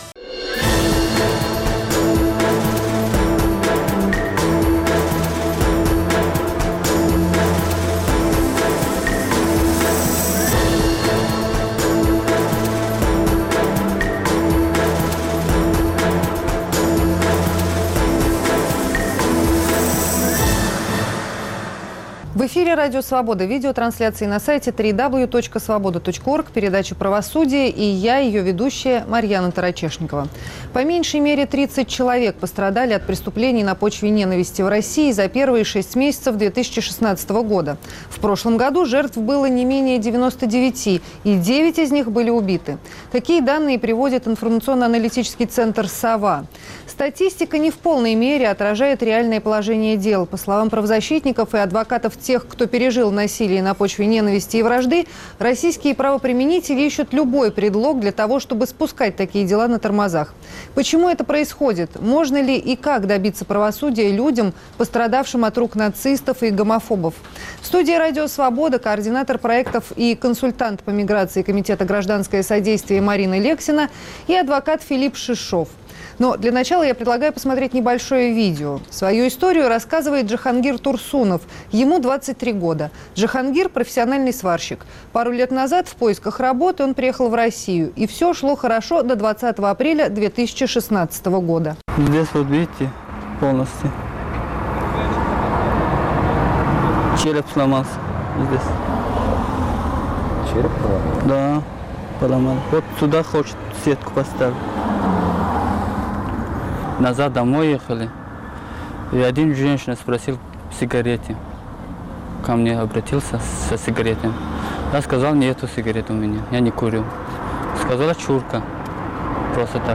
Как добиться правосудия пострадавшим от преступлений на почве ненависти и вражды. В студии Радио Свобода